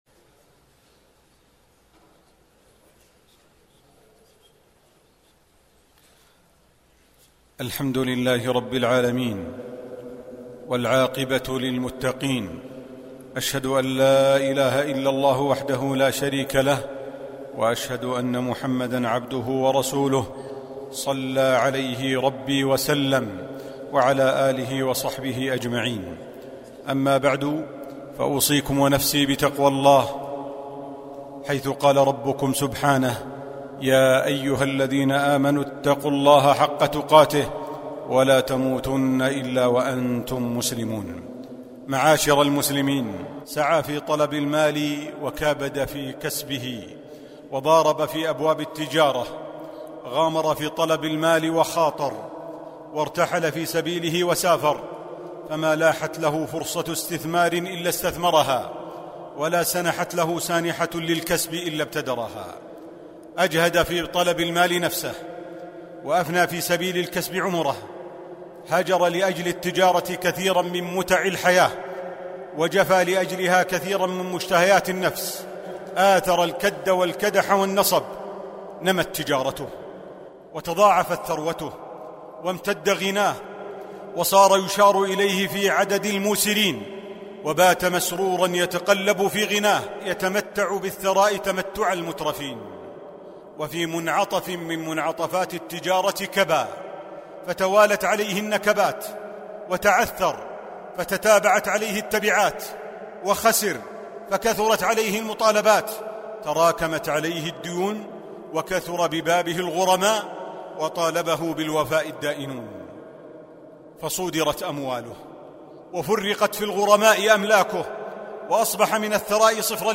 الخطب الصوتية